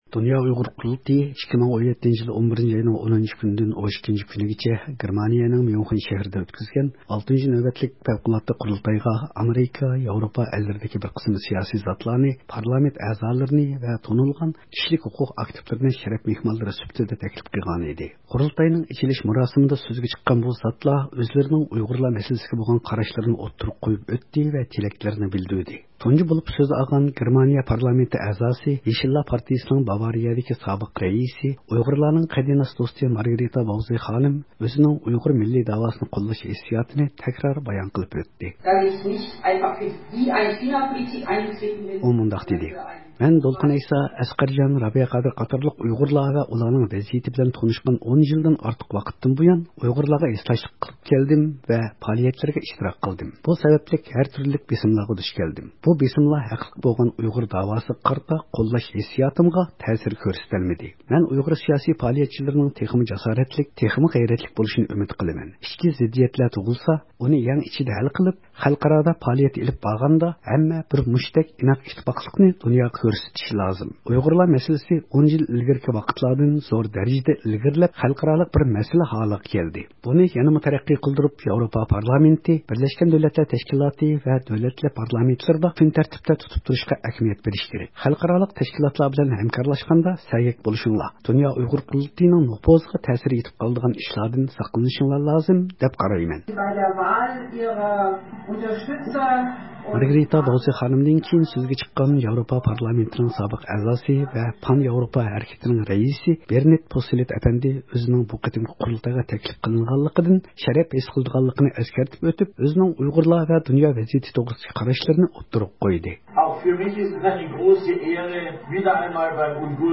ئەنگلىيەنىڭ سابىق ئىچكى ئىشلار مىنىستىرى نورمان باكېر سۆز قىلماقتا. 2017-يىلى 10-نويابىر، ميۇنخېن.
دۇنيا ئۇيغۇر قۇرۇلتىيىنىڭ 6-نۆۋەتلىك پەۋقۇلئاددە قۇرۇلتىيىدا چەتئەللەرنىڭ سىياسىي سەھنىلىرىدىكى زاتلار ۋە كىشىلىك ھوقۇق ئاكتىپلىرى ئۇيغۇرلار مەسىلىسى ھەققىدە توختالدى.
قۇرۇلتاينىڭ ئېچىلىش مۇراسىمىدا سۆزگە چىققان بۇ زاتلار ئۆزلىرىنىڭ ئۇيغۇر مەسىلىسىگە بولغان قاراشلىرىنى ئوتتۇرىغا قويۇپ ئۆتتى ۋە تىلەكلىرىنى بىلدۈردى.